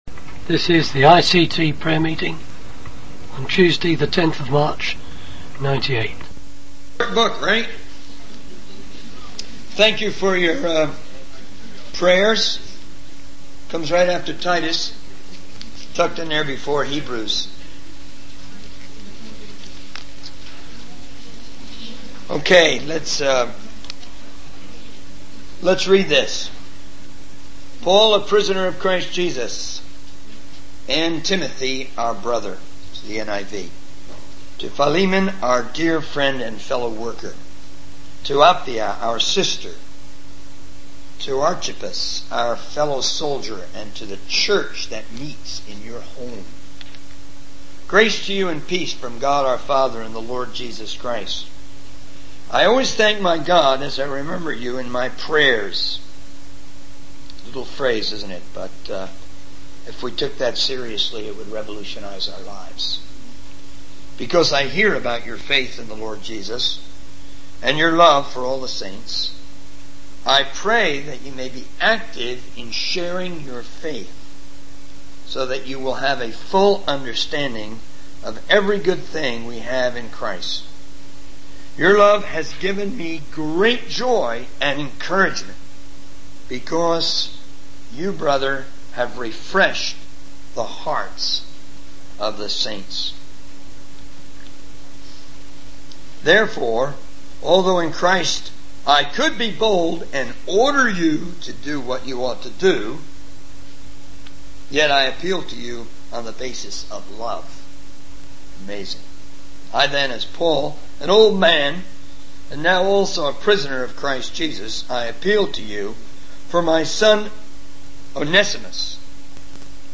In this sermon, the speaker reflects on his experience at Bible College and in an OM (Operation Mobilization) environment. He discusses the challenges of spirituality and how it can become overwhelming to absorb so much information.